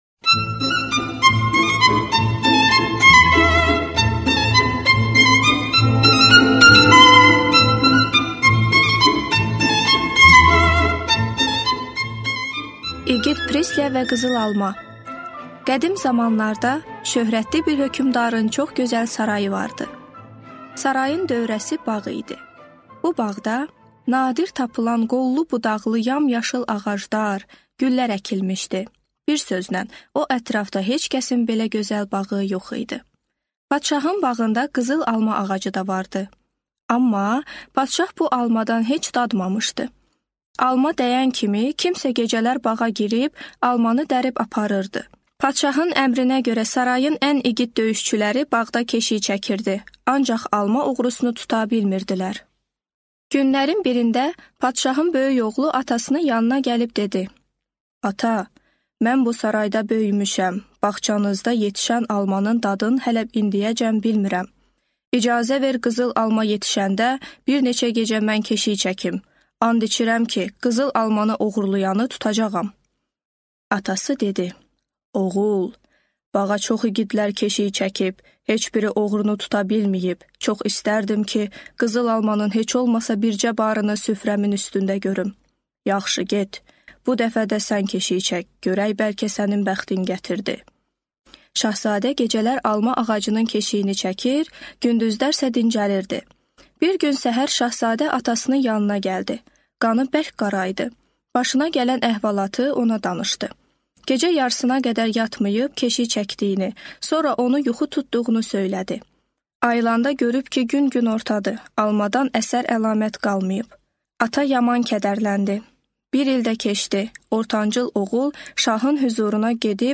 Аудиокнига Rumın nağılları | Библиотека аудиокниг